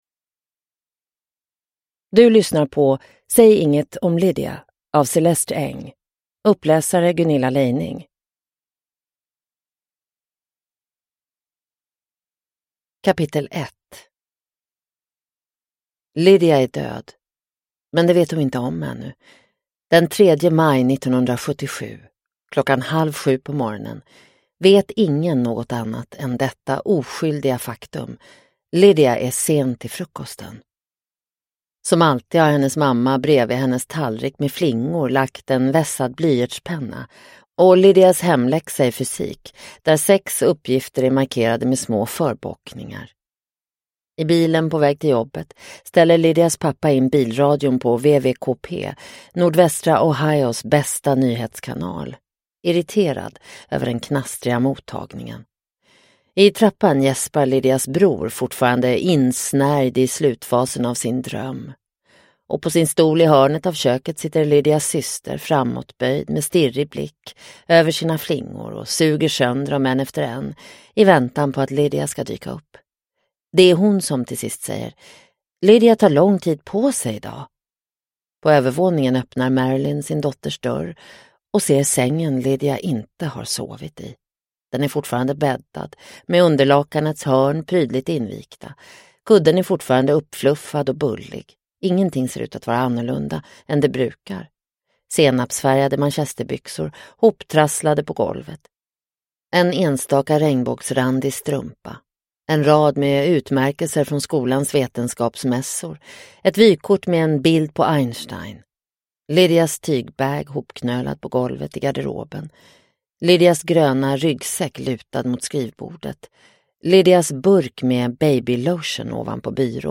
Säg inget om Lydia – Ljudbok – Laddas ner